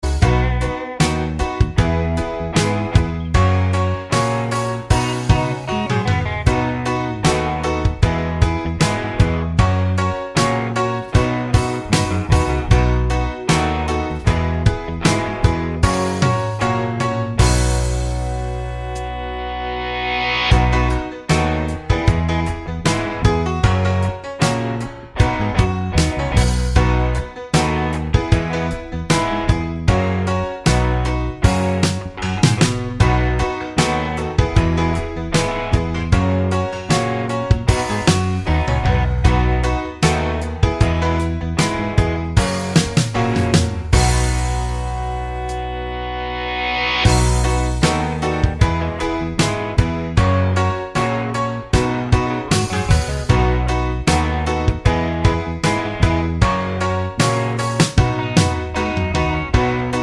no Backing Vocals Pop (1970s) 2:47 Buy £1.50